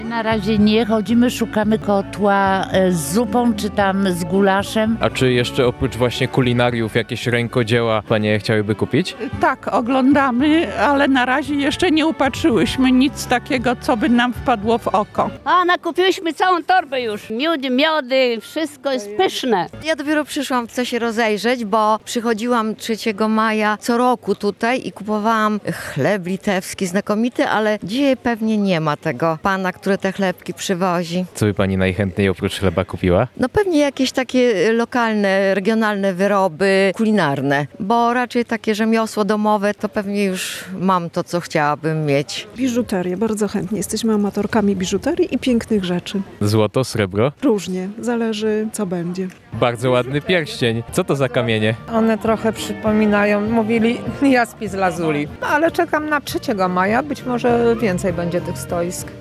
Jarmark-Rekodziela-Rzeszow-OBRAZEK.mp3